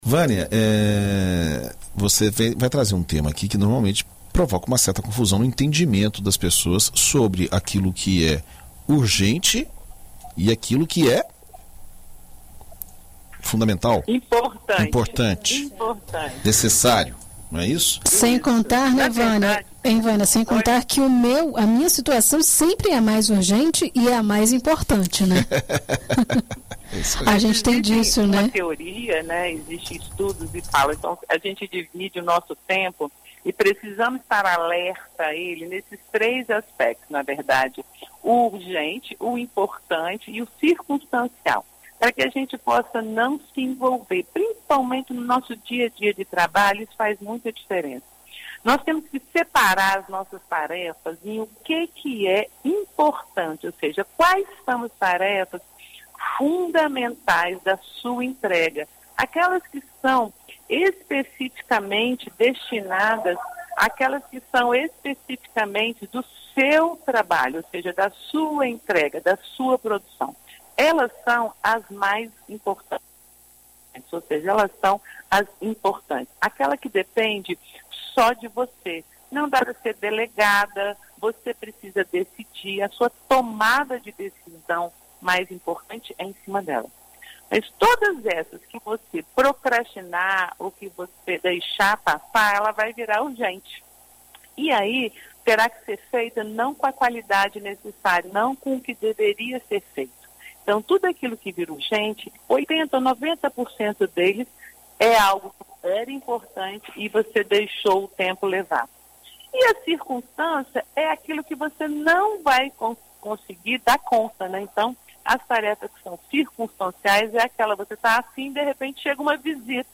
Na coluna Vida e Carreira desta quarta-feira (24), na BandNews FM Espírito Santo